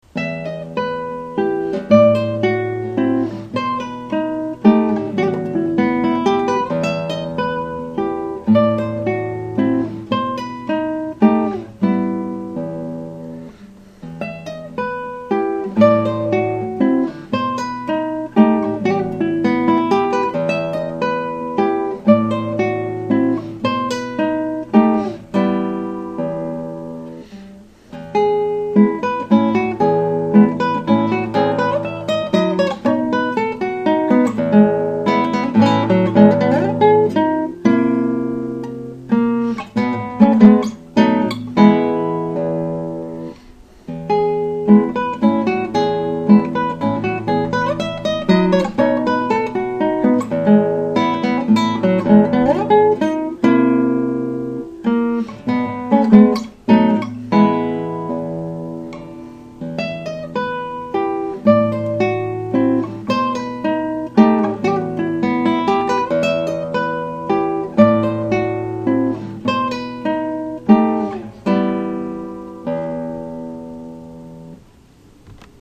Atlanta Guitarist for Hire
The ubiquitous “Adelita” by Francisco Tárrega is one of the most popular works for the guitar, and is standard repertoire for the intermediate guitarist.